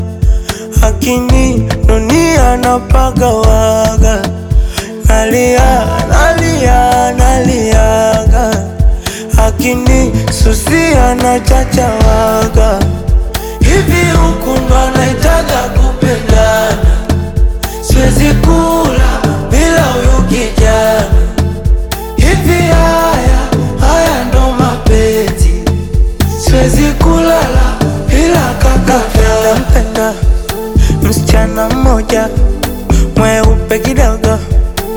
Жанр: Африканская музыка / Поп
# Afro-Pop